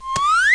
00416_Sound_KlopferGeist.Aus.mp3